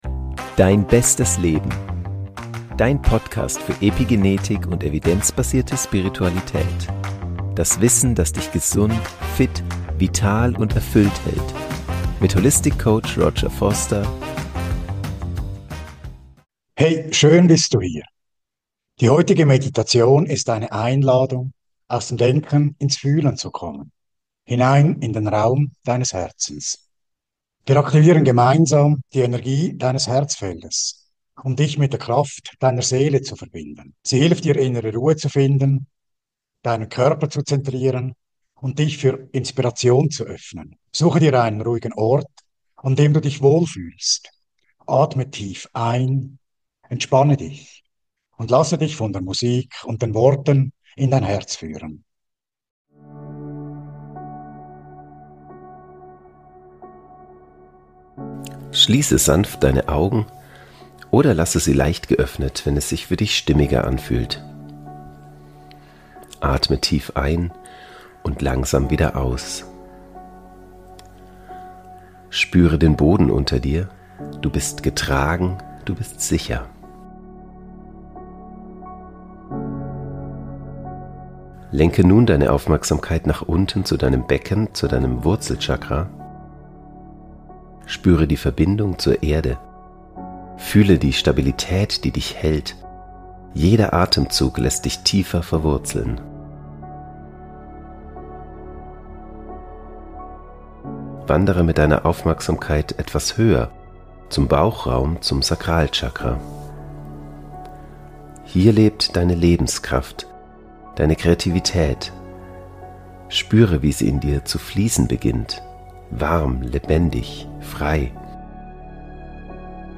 Meditationsreise